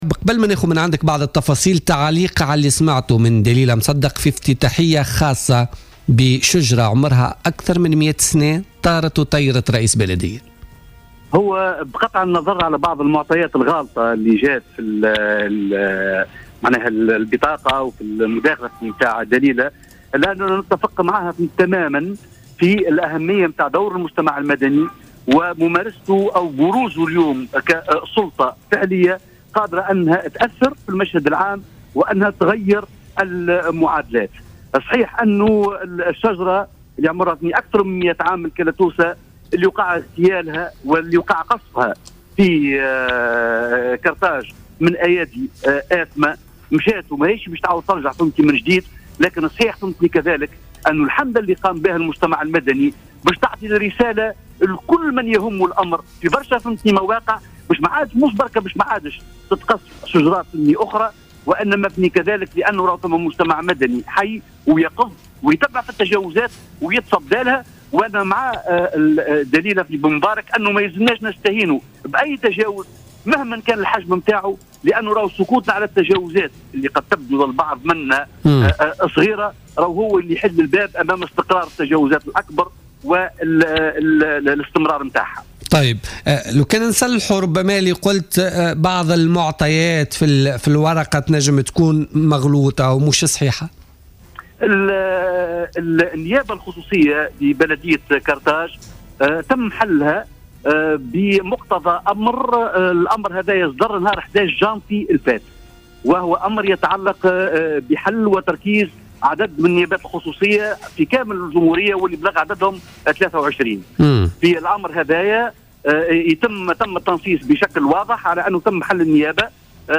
أكد زياد الهاني نائب رئيس النيابة الخصوصية لبلدية قرطاج في مداخلة له في "بوليتيكا" اليوم الخميس 25 فيفري 2016 أن الأخبار التي تم تداولها بخصوص الإطاحة بالنيابة الخصوصية بقرطاج بسبب قطع شجرة "الأوكالبتوس" لا أساس لها من الصحة.